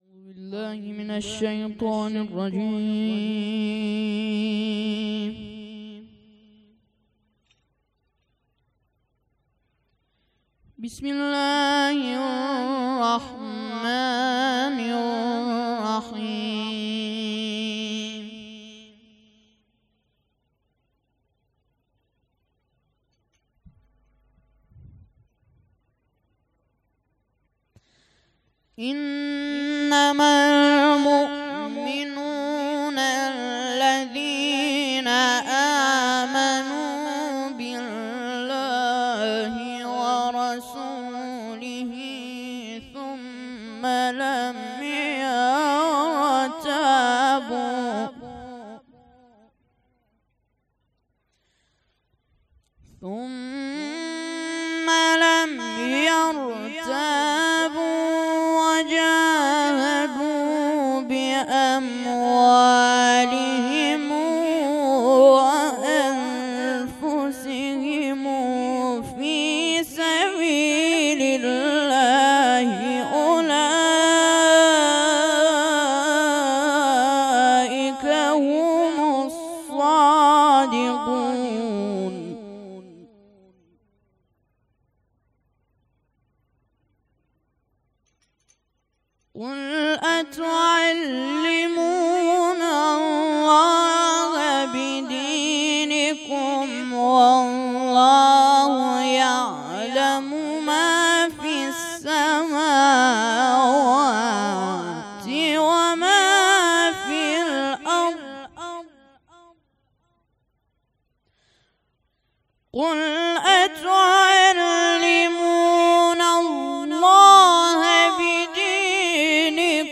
مراسم عزاداری محرم ۱۴۰۴